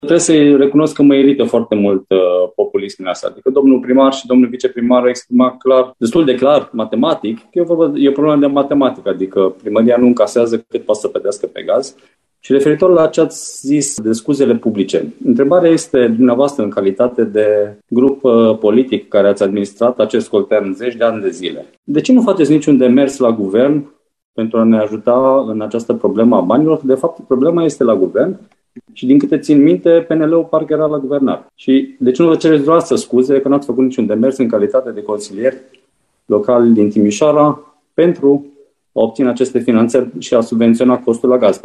De cealaltă parte, consilierul USR Răzvan Negrișanu l-a acuzat pe liderul consilierilor PNL de populism, cerându-i să facă demersuri la Guvern pentru a ajuta Timișoara: